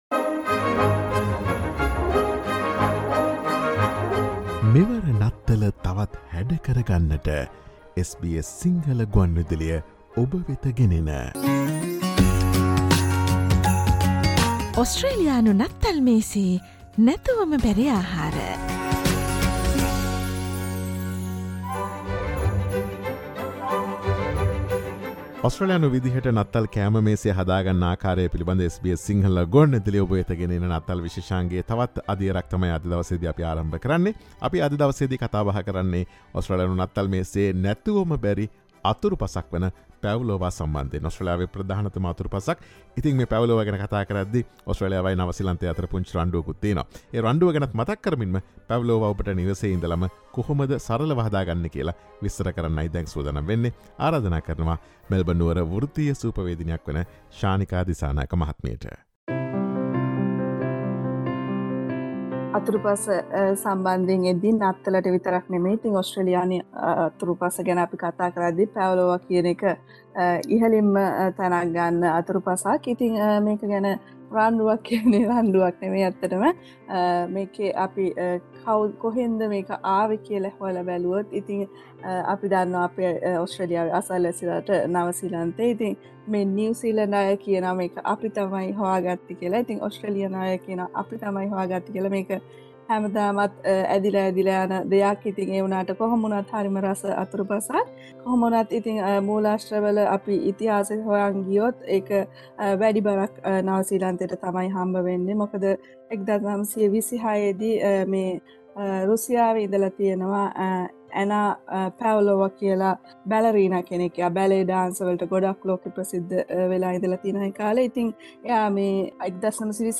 ඔස්ට්‍රේලියානු නත්තල් මේසයේ නැතුවම බැරි ආහාර යටතේ ඔස්ට්‍රේලියාවේ ජනප්‍රියතම අතුරුපසක් වන Pavlova මෙවර නත්තල සඳහා සාදාගන්නා අයුරු පැහැදිලි කරන SBS සිංහල ගුවන් විදුලියේ නත්තල් විශේෂාංගයට සවන් දෙන්න.